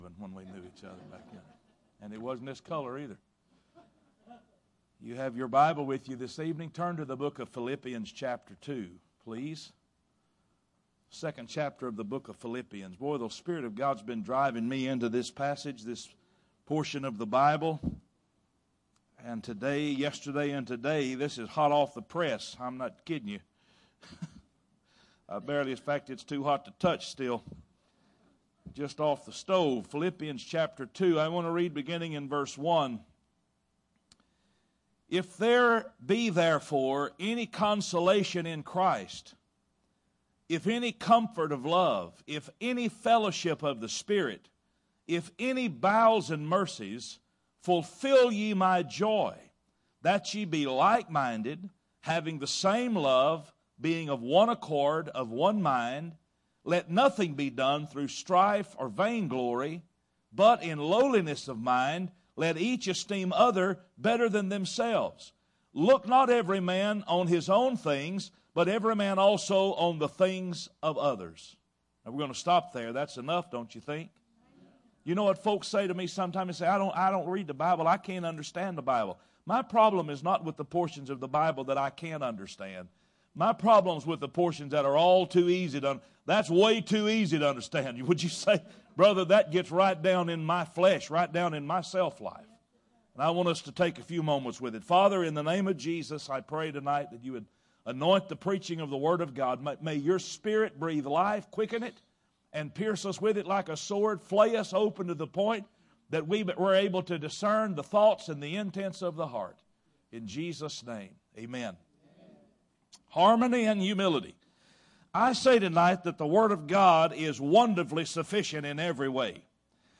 Bible Text: Philippians 2:1-4 | Preacher